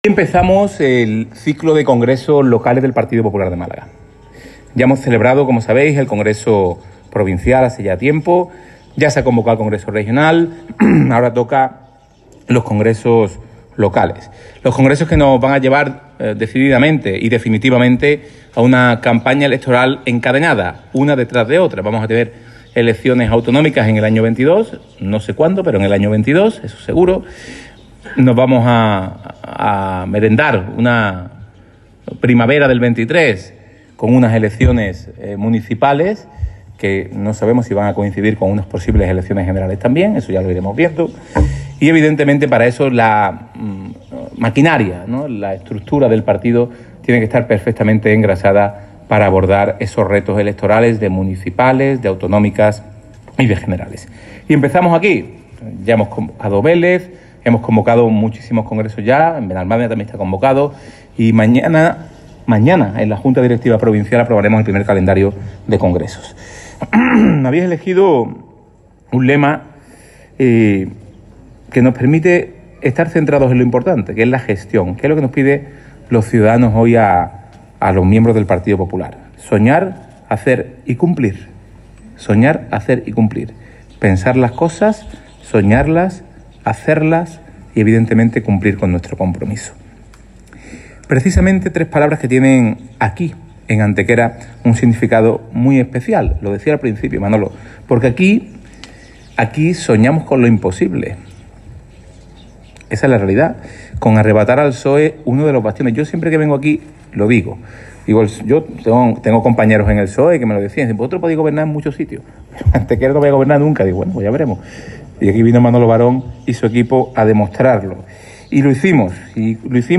Así lo ha expuesto durante la inauguración del Congreso del PP de Antequera